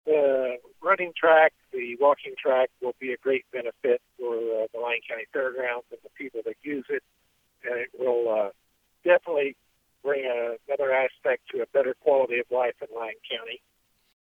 Blustery winds and high temperatures were not enough to keep the spirits down at the grand opening for the Lyon County Fairgrounds multi-use pathway on Thursday.
2134-martin-excited.wav